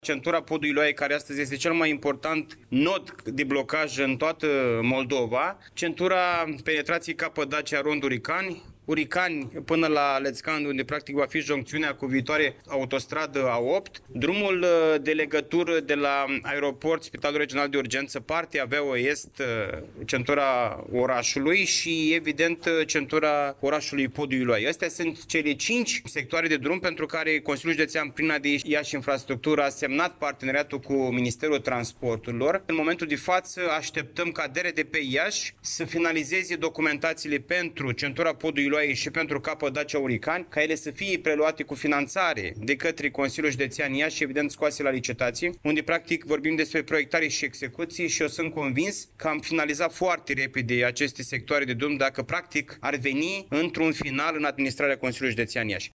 Președintele CJ Iași, Costel Alexe: